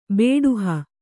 ♪ bēḍuha